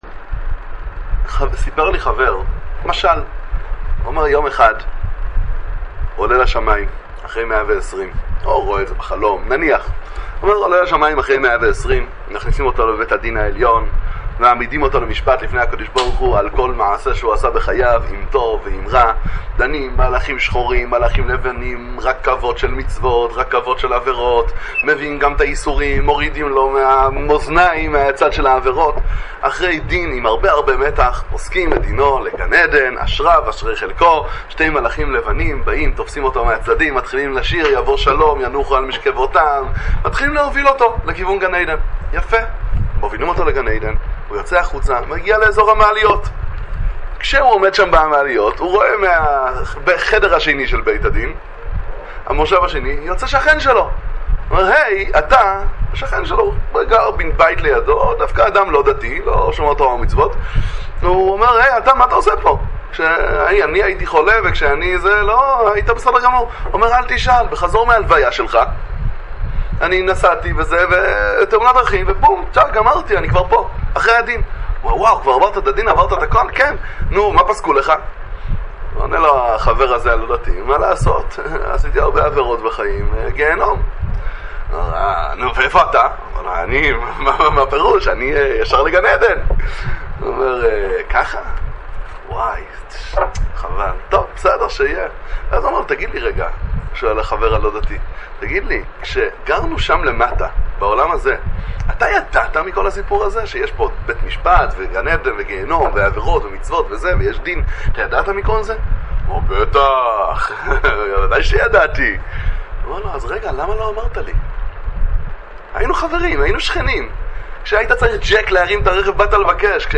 דבר תורה קצר לפרשת השבוע